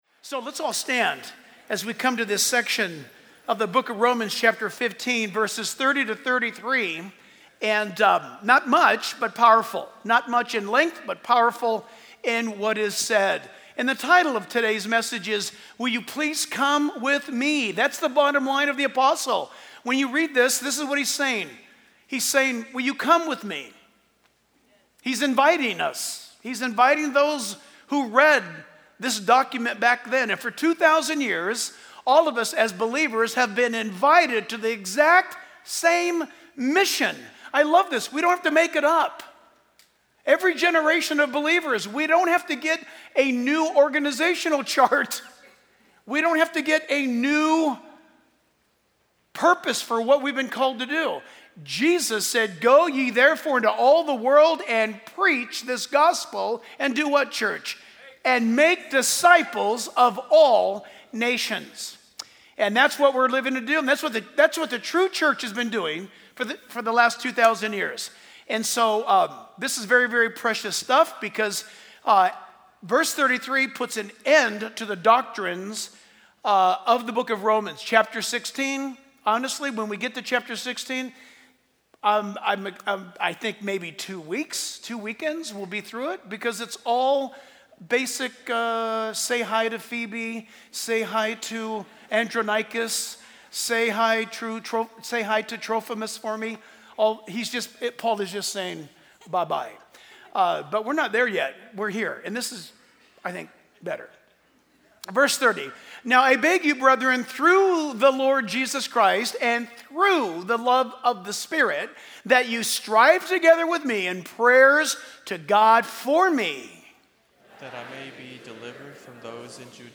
Reference: Romans 15:30-33 Download Sermon MP3 Download Sermon Notes